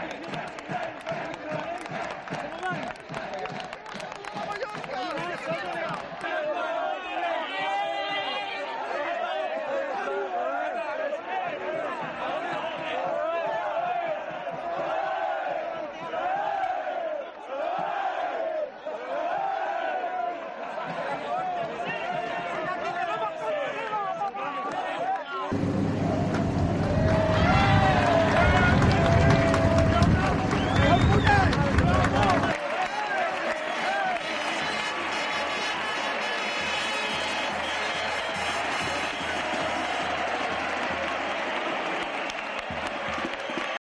Los sonidos del ascenso histórico de Vallecas que acabó con Beltrán en la ducha